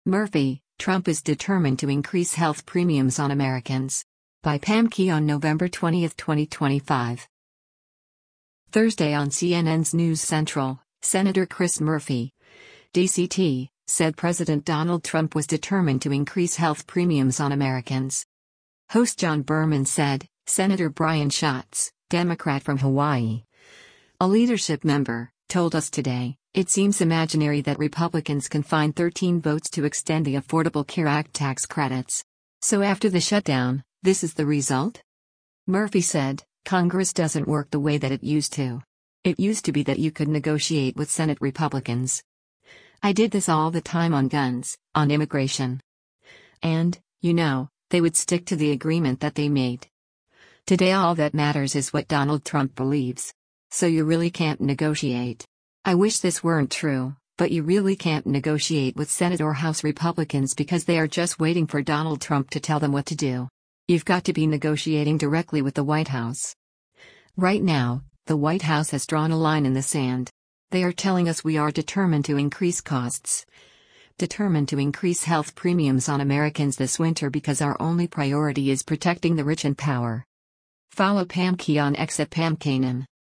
Thursday on CNN’s “News Central,” Sen. Chris Murphy (D-CT) said President Donald Trump was “determined to increase health premiums on Americans.”